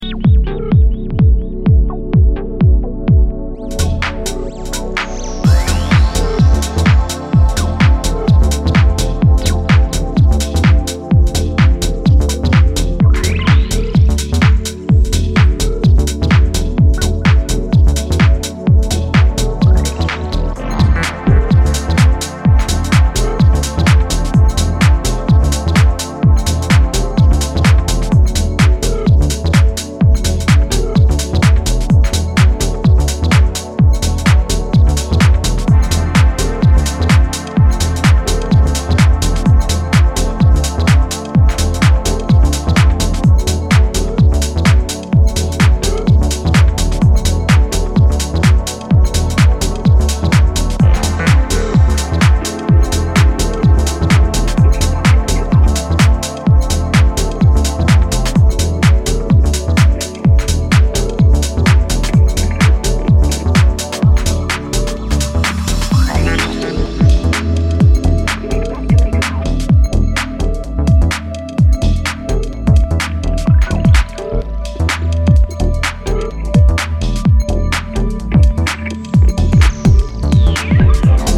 a new various artists LP a bit more clubby than usual